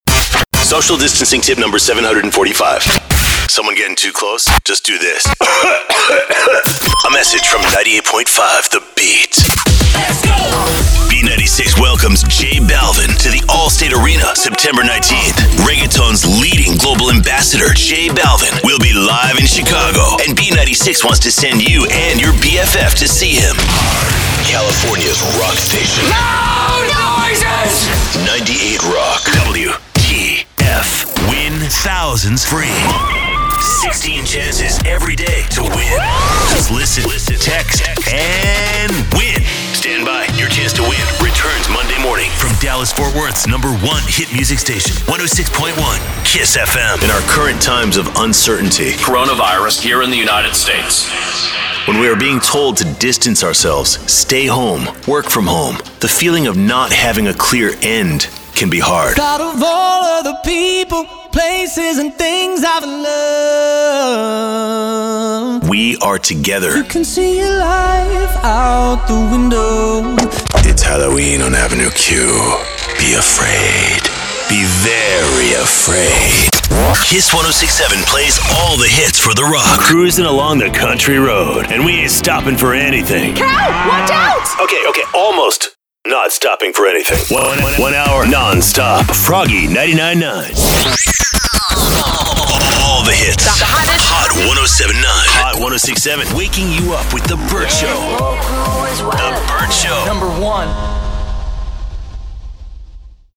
Dynamic Voice Talent ✨ for all media